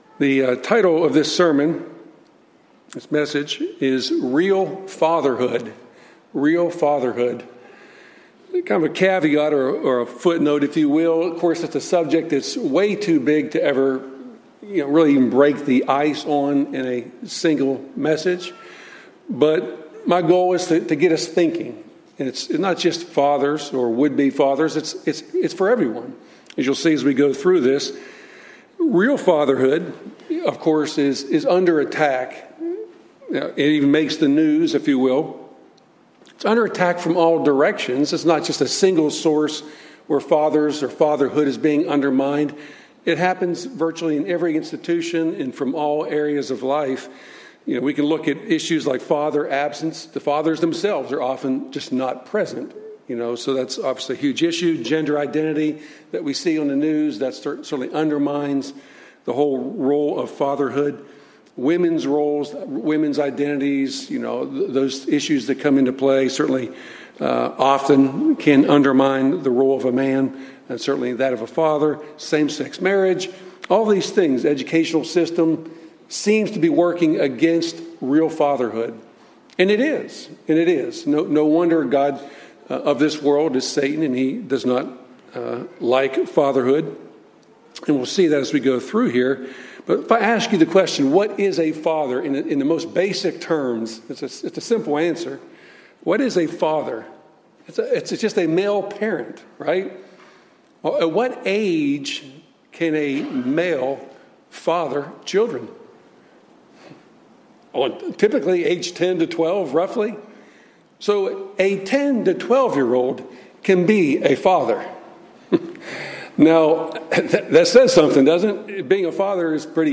Sermons
Given in Nashville, TN